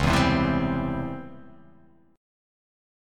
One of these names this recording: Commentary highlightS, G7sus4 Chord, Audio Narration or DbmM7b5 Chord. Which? DbmM7b5 Chord